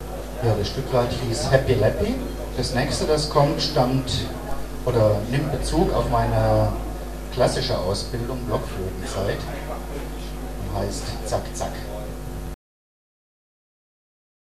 Ansage